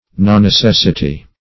Search Result for " nonnecessity" : The Collaborative International Dictionary of English v.0.48: Nonnecessity \Non`ne*ces"si*ty\, n. Absence of necessity; the quality or state of being unnecessary.
nonnecessity.mp3